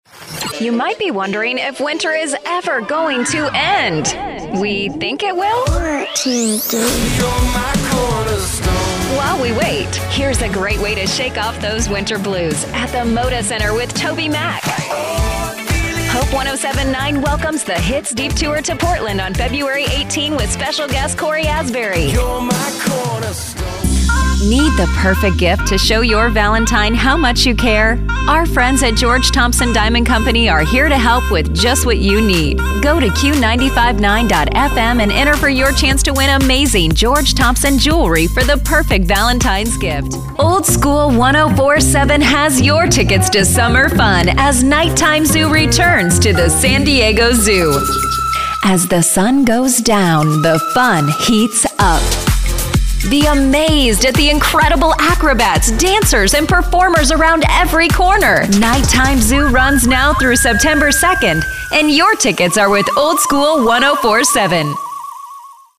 Female
My voice is youthful, upbeat, conversational and relatable.
Radio / TV Imaging
Radio Imaging Demo
Words that describe my voice are Engaging, Conversational, Relatable.